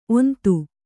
♪ ontu